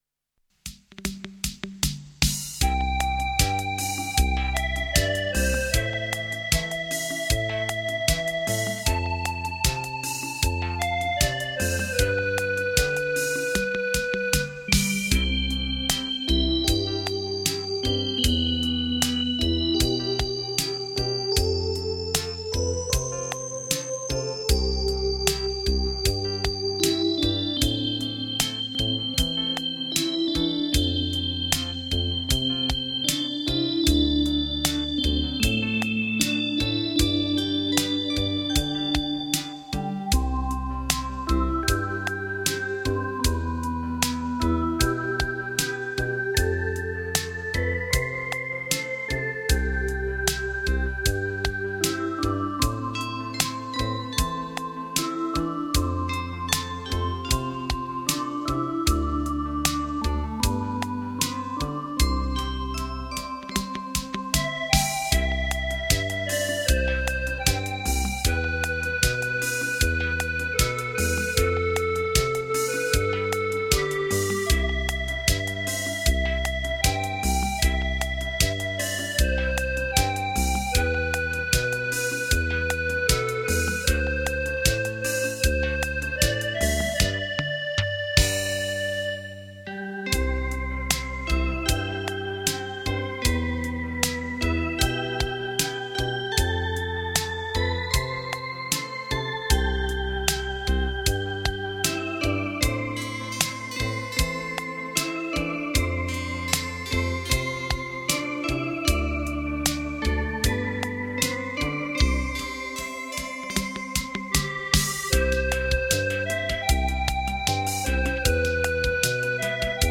曼妙音符如蜻蜓点水般轻柔掠过
HI-FI音响 三维环绕临场感音效
环绕音效 美声天籁